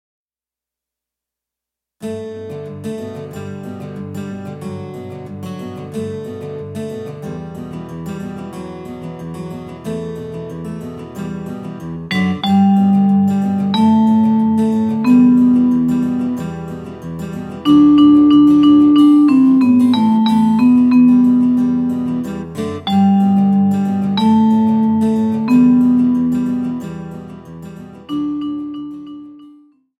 Saxophone Ténor